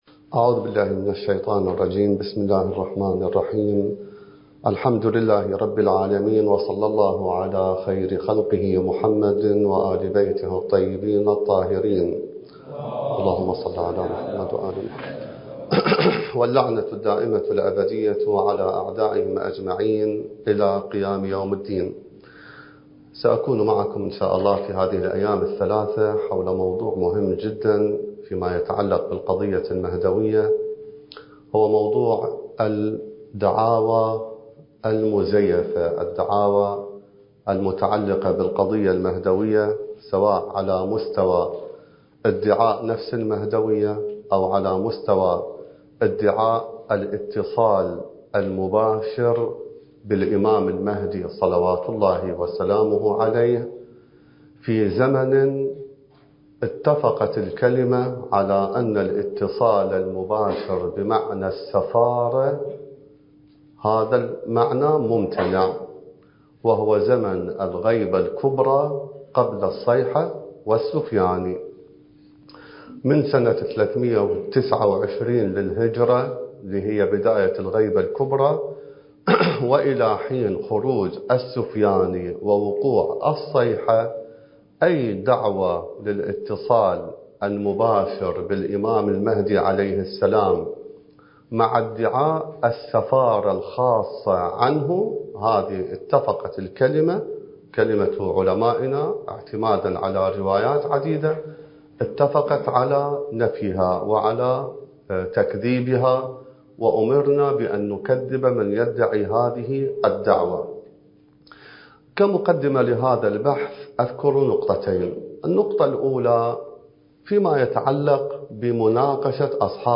الدورة المهدوية الأولى المكثفة (المحاضرة العاشرة) التي أقامها معهد تراث الأنبياء (عليهم السلام) للدراسات الحوزوية الألكترونية التابع للعتبة العباسية المقدسة وبالتعاون مع مركز الدراسات التخصصية في الإمام المهدي (عجّل الله فرجه) ومدرسة دار العلم للإمام الخوئي (قدس سره) المكان: النجف الأشرف التاريخ:2024